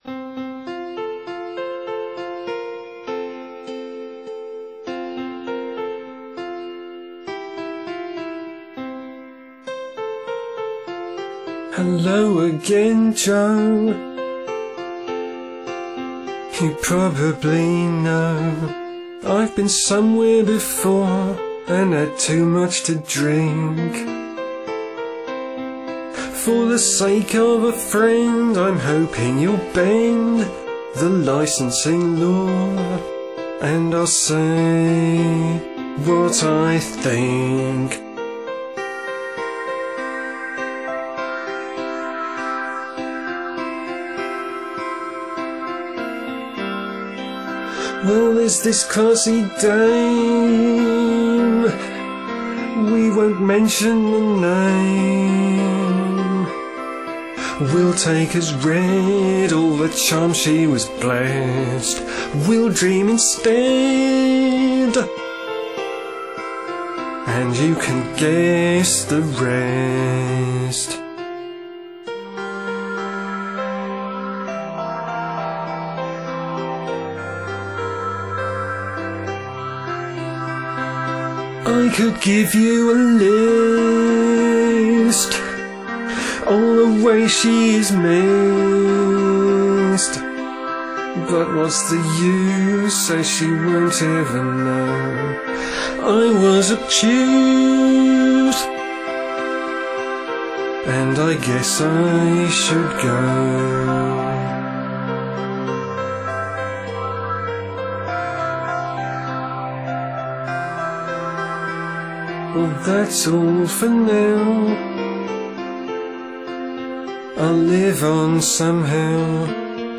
One more drink - I had a streaming cold when I recorded this, which may or may not have been fortuitous.
The piano actually had a script.
You'll notice it balances in the middle - it's what you might call an A-B-B-A structure.